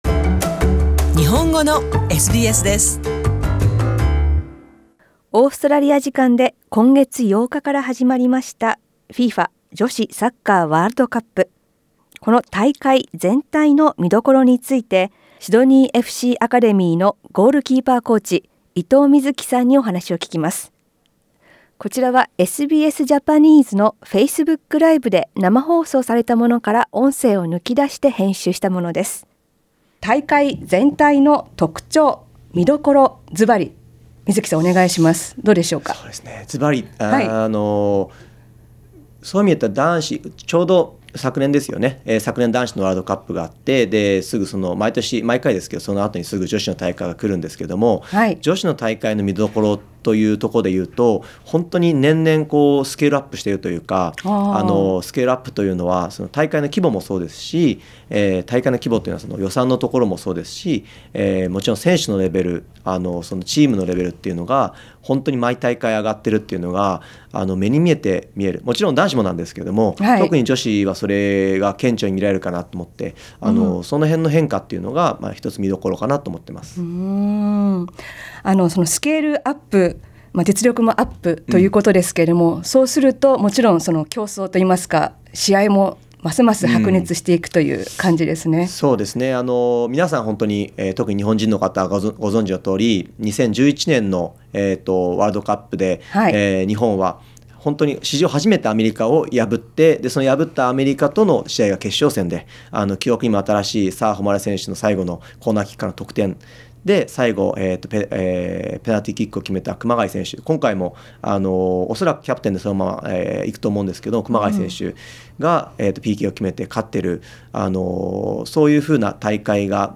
SKIP ADVERTISEMENT このインタビューは、 日本語ラジオのフェイスブック で5月31日にライブ配信された内容を編集したものです。